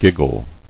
[gig·gle]